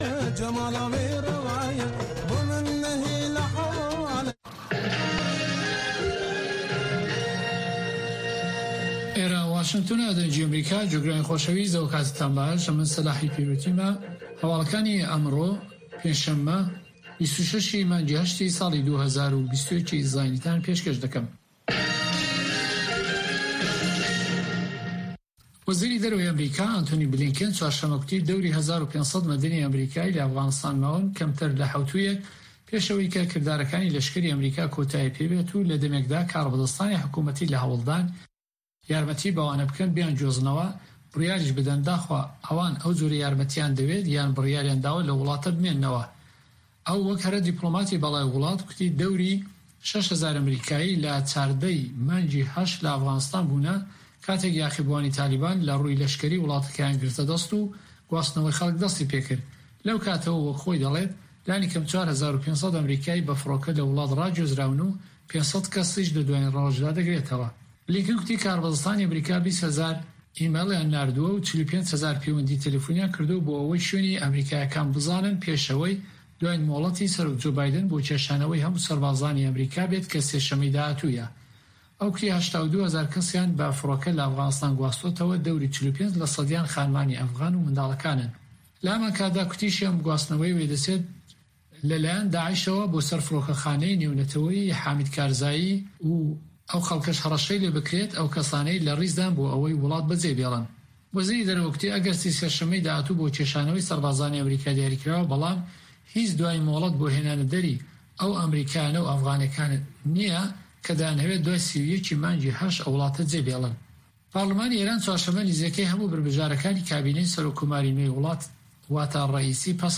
هەواڵەکانی 1 ی شەو